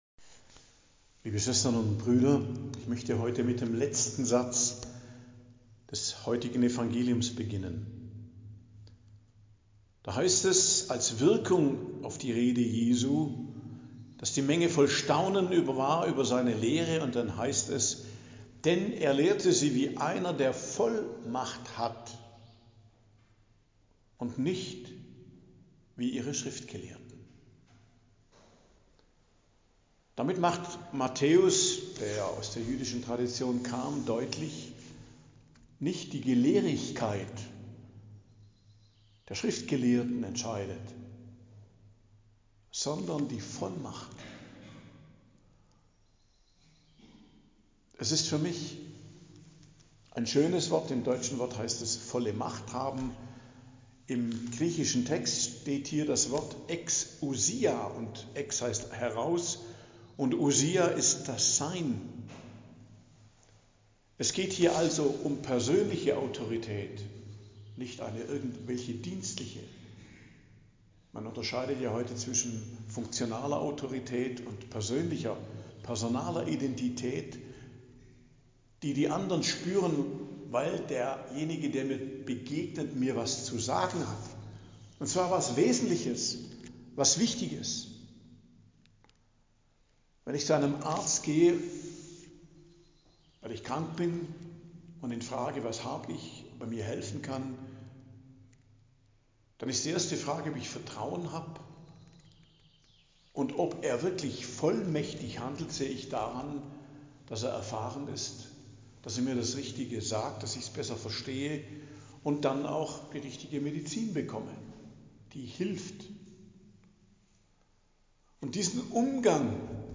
Predigt am Dienstag der 12. Woche i.J., 26.06.2025